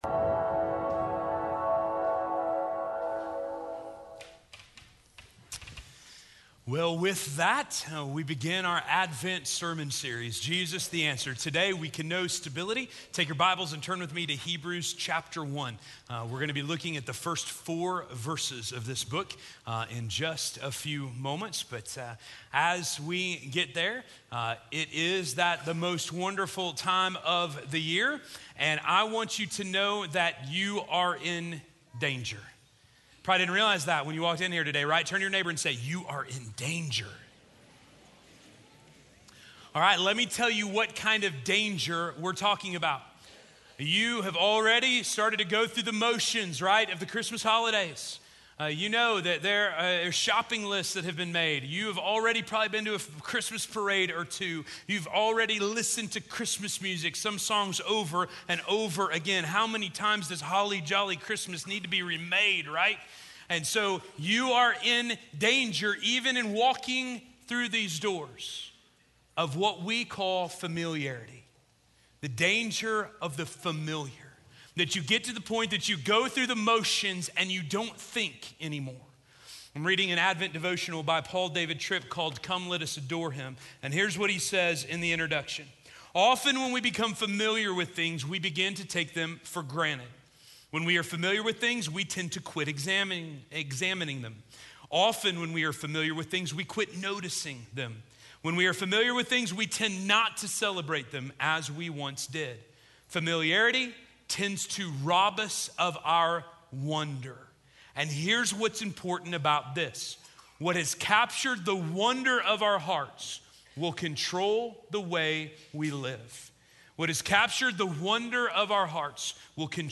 We Can Know...Stability - Sermon - Station Hill